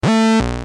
Free MP3 vintage Korg PS3100 loops & sound effects 4
Korg - PS-3100 62